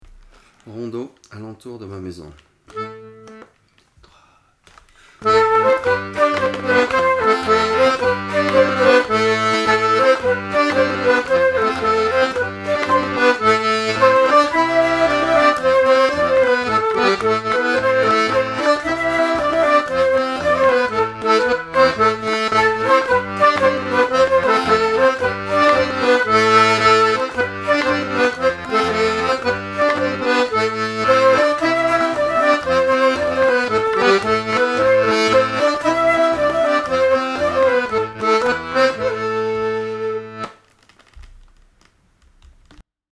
4 nouveaux morceaux pour l'année : un nouveau rondeau qui vient remplacer notre las hemnas et la pieternelle qui vient remplacer la scottich des freres lambert (c'est une musique/danse du nord de France)  et Uskudara, un morceau turc pouvant se danser en scottich, et une tarentelle A Lu Mircatu.